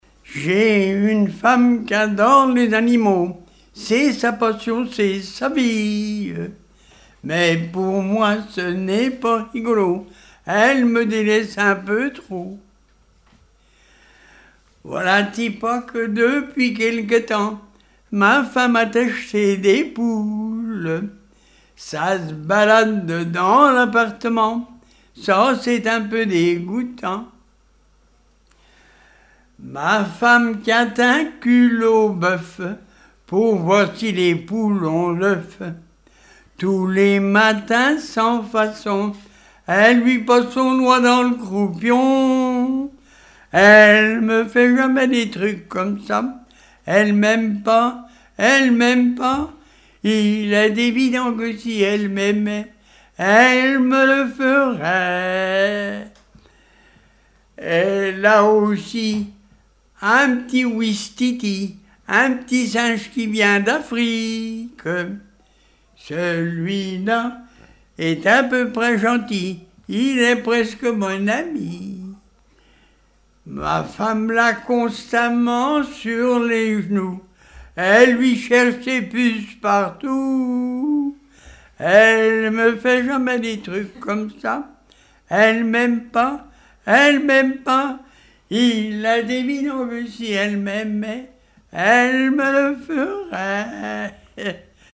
Chansons et témoignages
Pièce musicale inédite